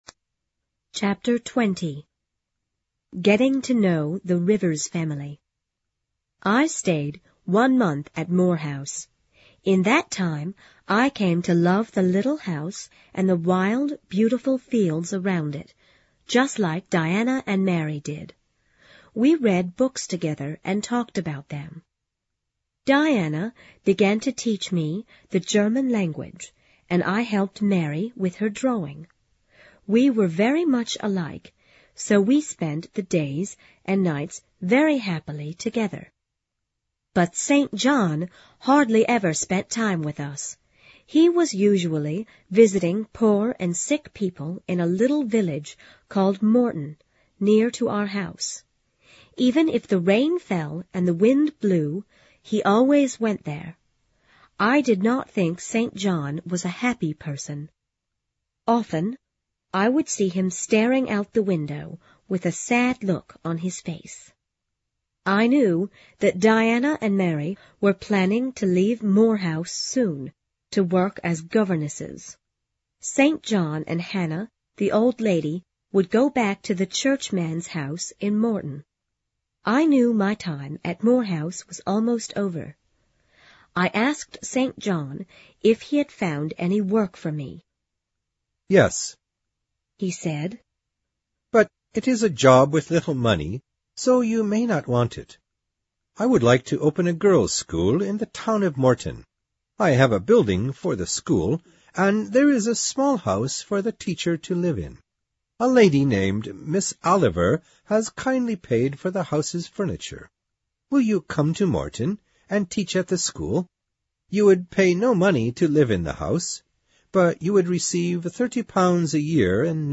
有声名著之简爱Jene Eyer Chapter20 听力文件下载—在线英语听力室